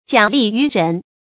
假力于人 注音： ㄐㄧㄚˇ ㄌㄧˋ ㄧㄩˊ ㄖㄣˊ 讀音讀法： 意思解釋： 借別人的力量做事。